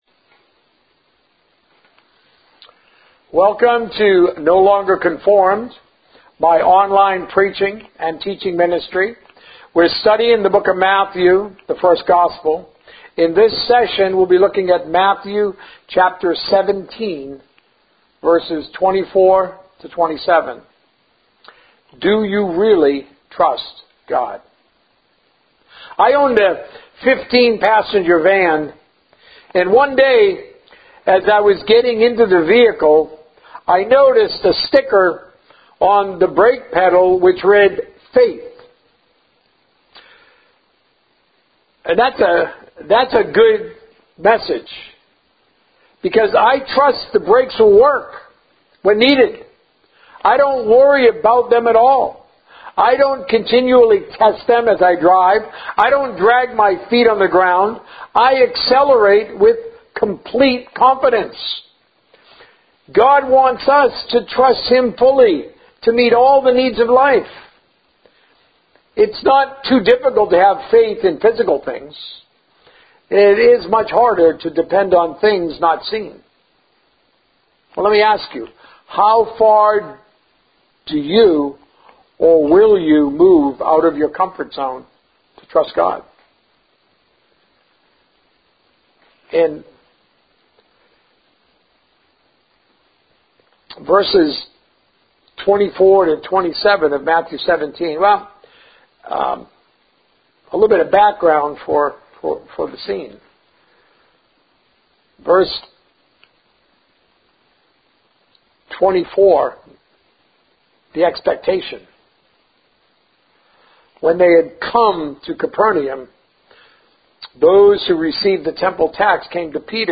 A message from the series "The First Gospel." Do You Really Trust God?